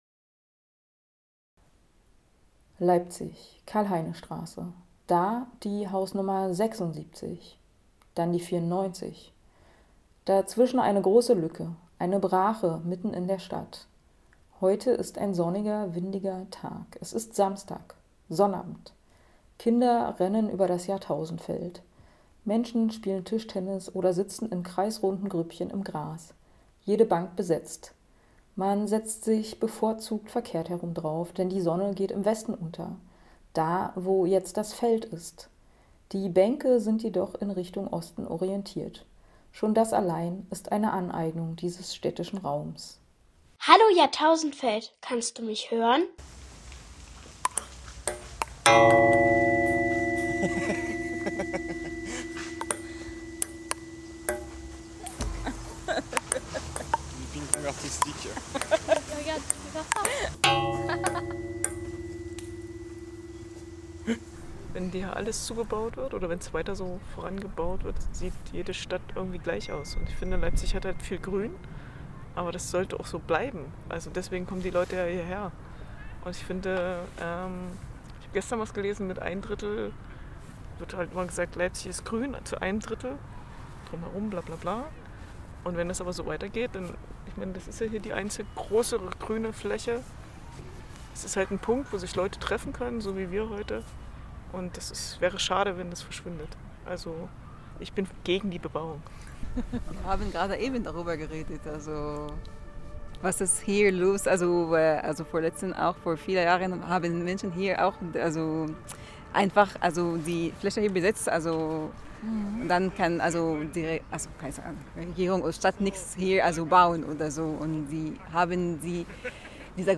Stimmen auf dem Jahrtausendfeld im März 2025 (Datei herunterladen):